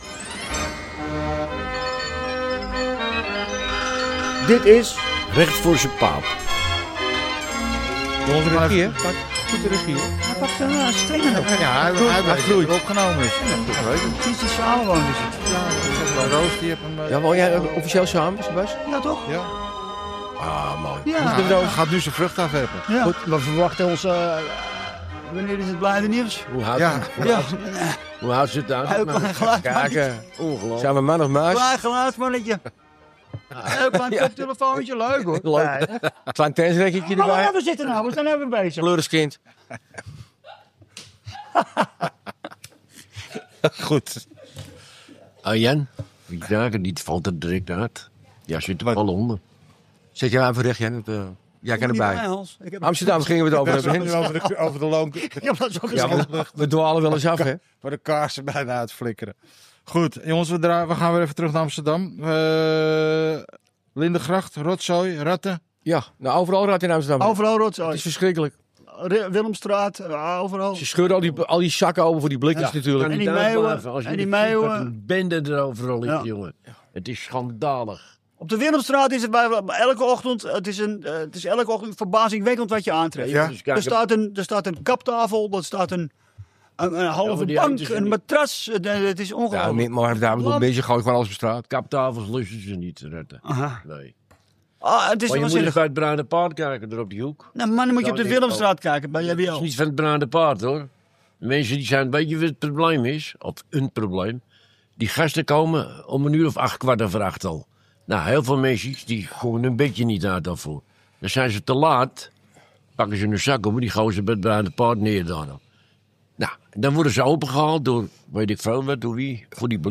Dé podcast van de Jordaan, waarin vier Jordanezen, aan de ronde tafel in Café ’t Papeneiland aan de Prinsengracht, het leven in de meest iconische buurt van Nederland bespreken.